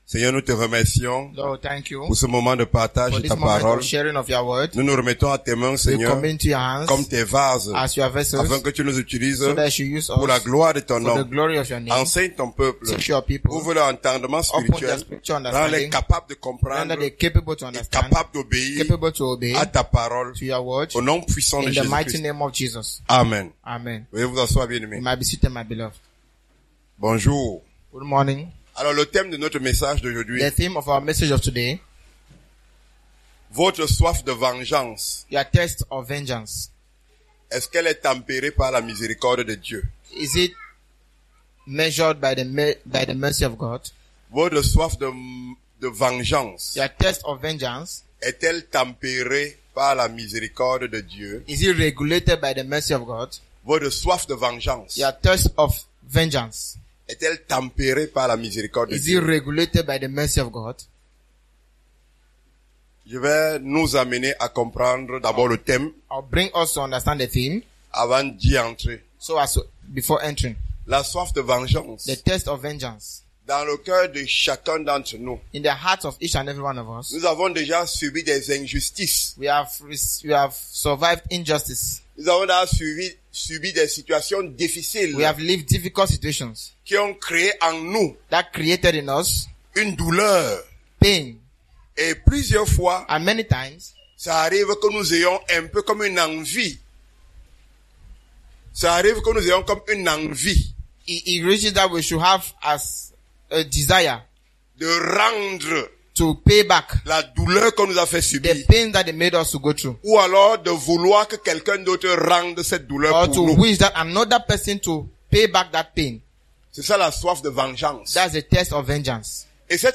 Ce message a été enregistré le dimanche 02 Juin 2024 pendant la prédication